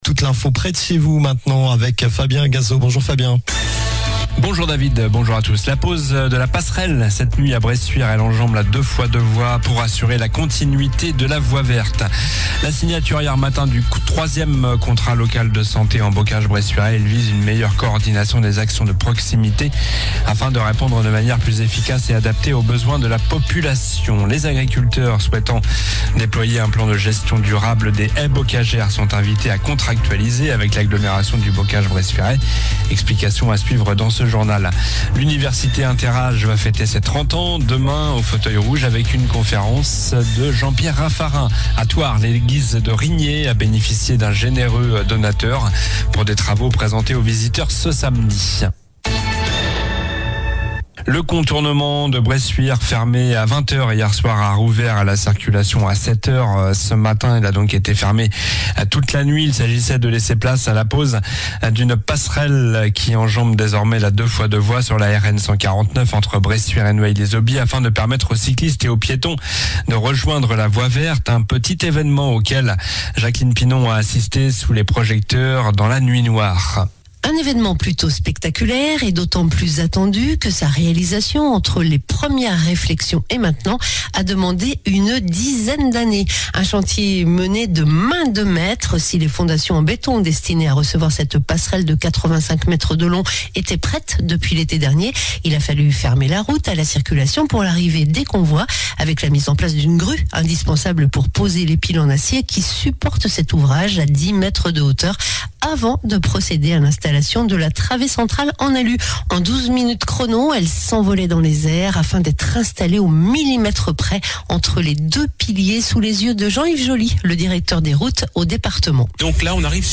Journal du jeudi 27 novembre (midi)